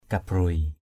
/ka-broɪ/ (d.) hôm qua = hier. amaik barau nao mâng kabruai a=mK br~@ _n< m/ k=b& mẹ vừa mới đi ngày hôm qua. ngap blaoh mâng kabruai ZP _b*<H m/ k=b&...